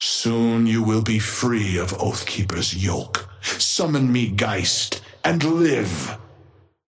Amber hand voice line - Soon you will be free of Oathkeeper's yoke.
Patron_male_ally_ghost_oathkeeper_5i_start_05.mp3